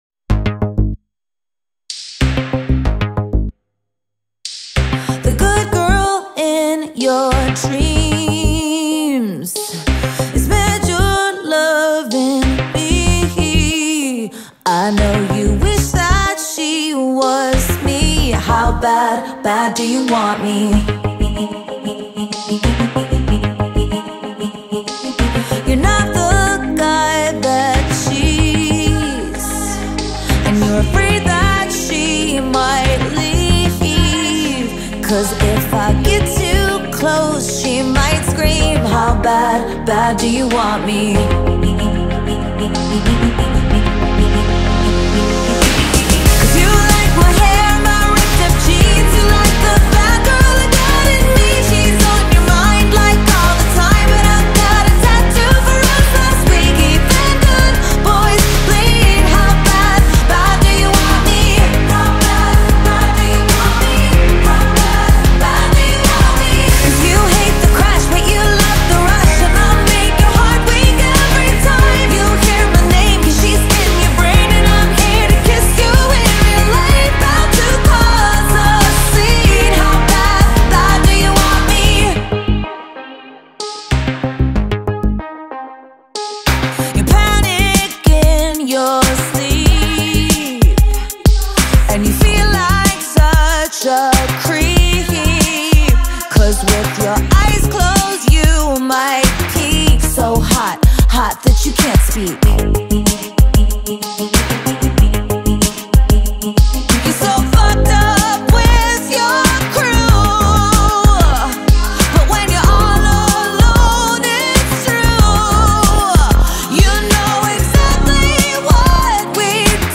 ترکیبی از پاپ، راک و موسیقی الکترونیک رو به کار برده